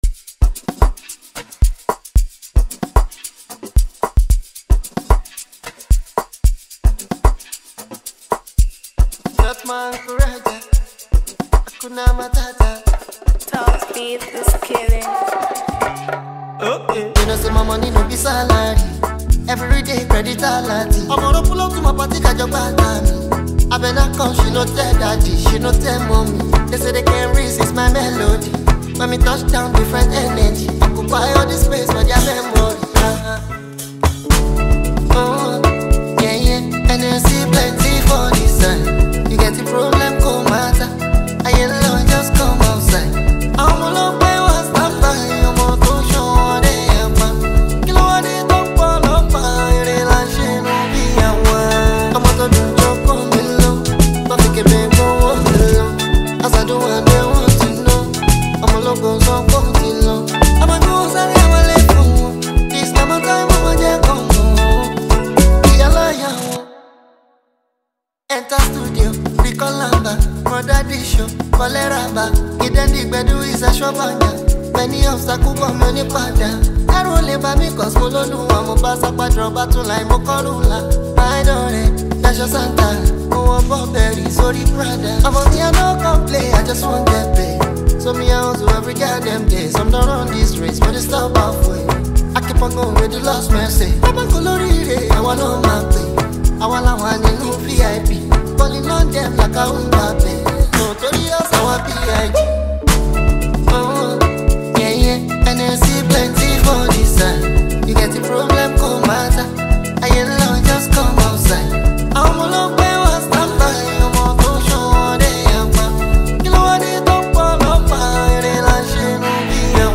Nigerian Afro-pop singer
Afrobeats